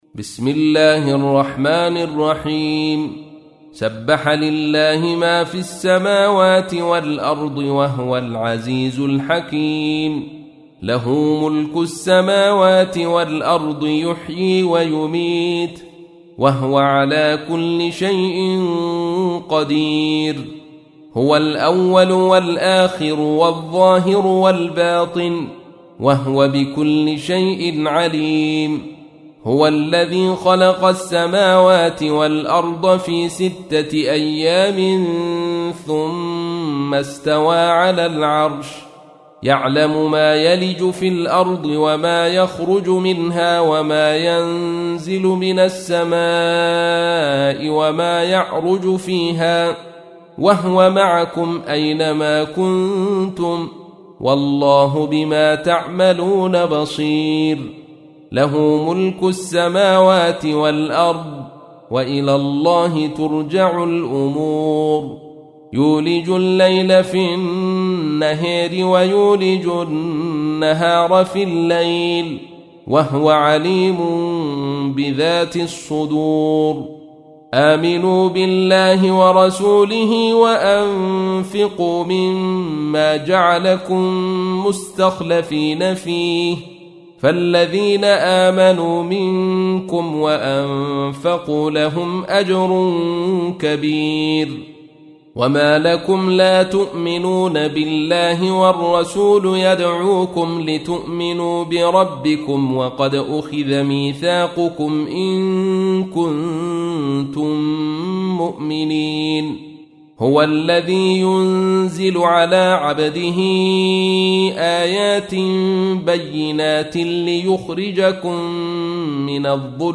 تحميل : 57. سورة الحديد / القارئ عبد الرشيد صوفي / القرآن الكريم / موقع يا حسين